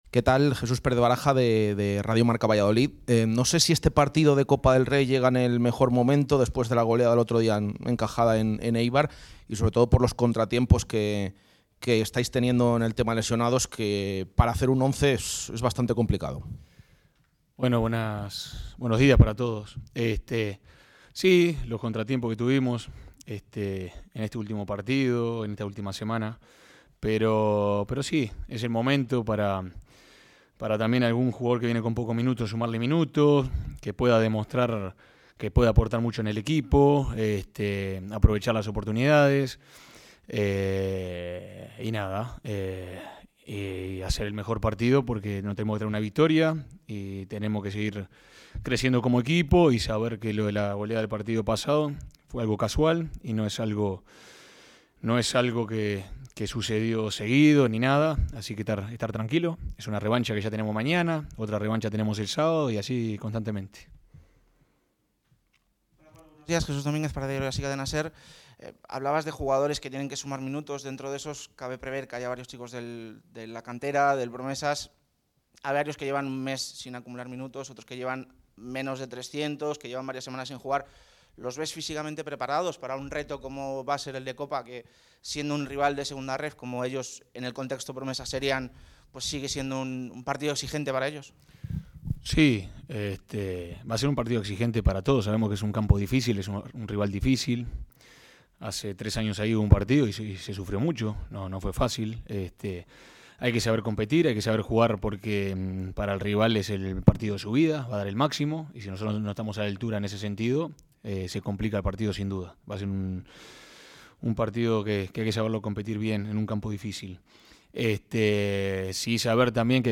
rueda de prensa completa.